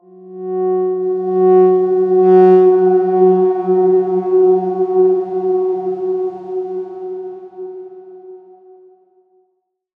X_Darkswarm-F#3-pp.wav